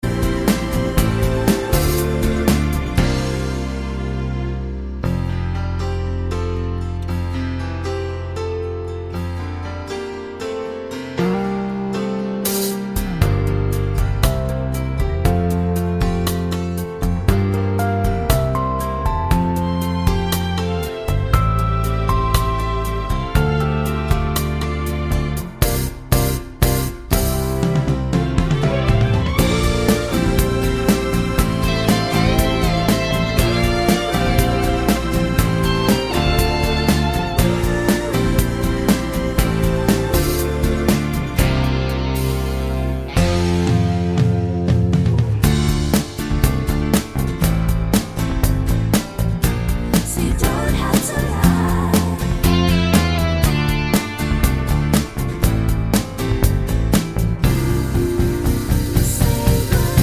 Minus Harmony Pop (1970s) 2:47 Buy £1.50